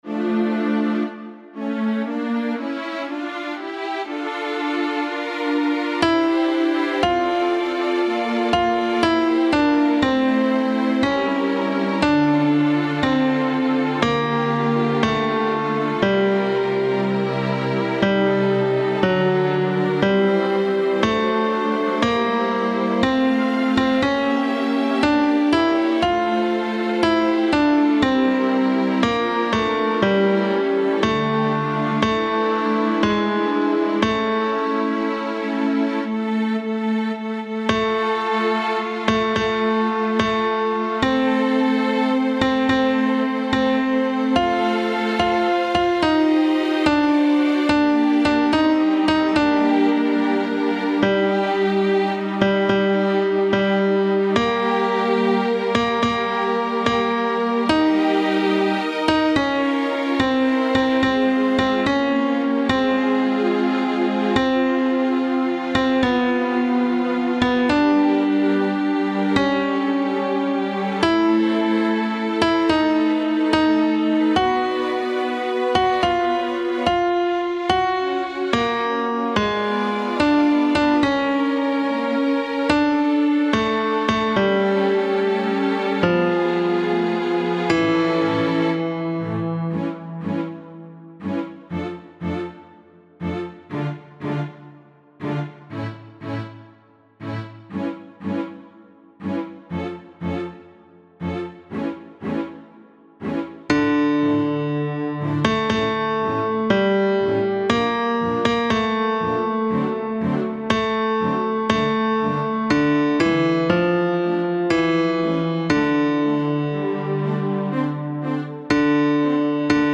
Tenor II
Mp3 Música